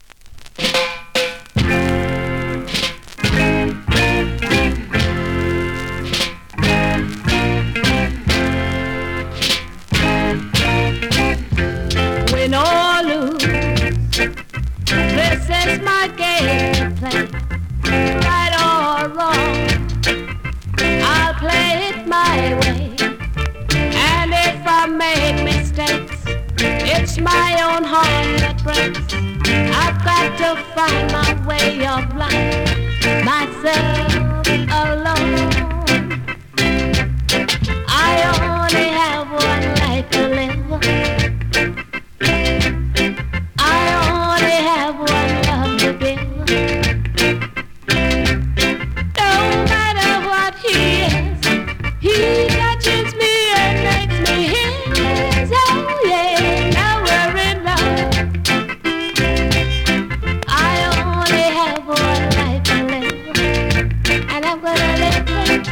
コンディションVG+(少しプチプチ)
スリキズ、ノイズ比較的少なめで